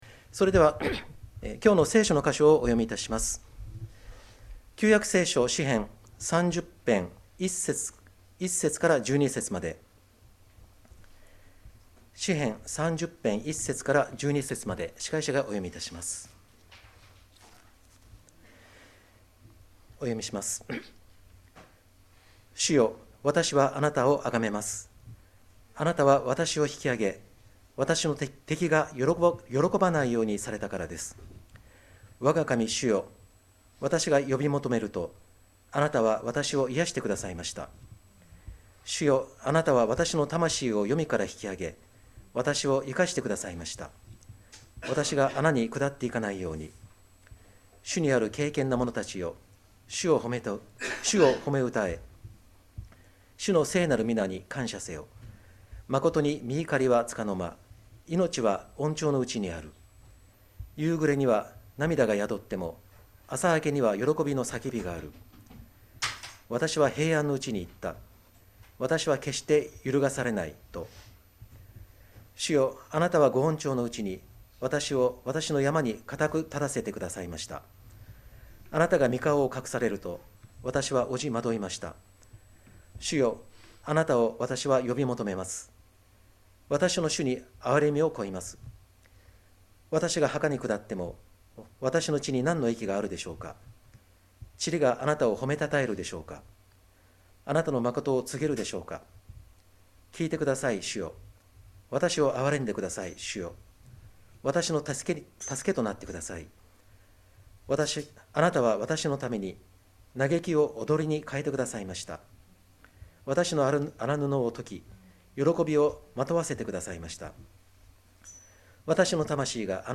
2024年12月29日礼拝 説教 「朝明けには喜びの叫びがある」 – 海浜幕張めぐみ教会 – Kaihin Makuhari Grace Church